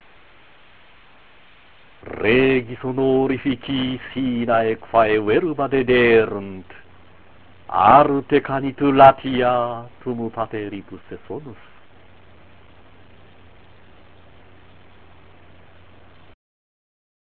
朗読９-10行　　朗読11-12行　　朗読13-14行　　朗読15-16行